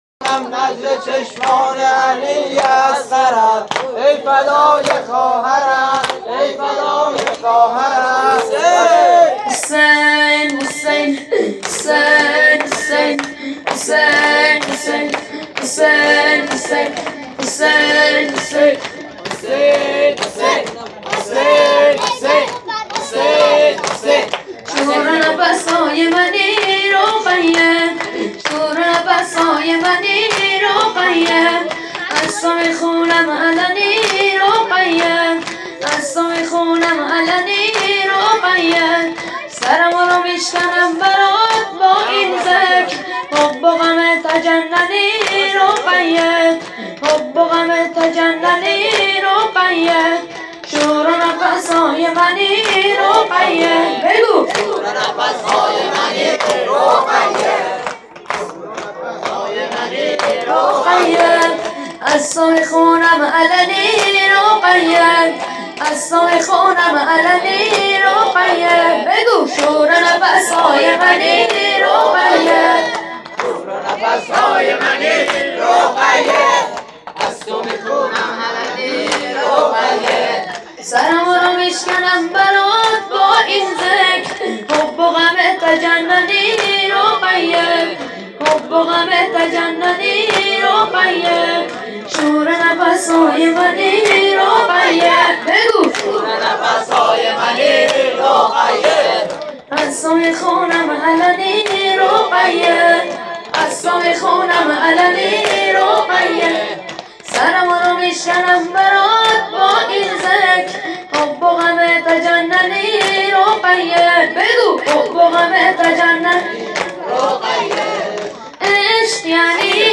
نوحه شور نفس های منی رقیه